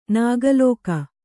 ♪ nāga lōka